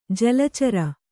♪ jala cara